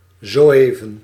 Ääntäminen
IPA : /ˈdʒʌst/ US : IPA : [dʒʌst]